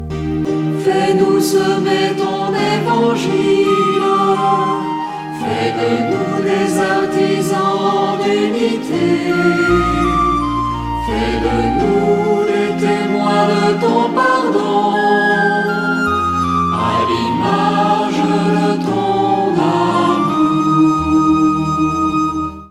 ♫ Refrain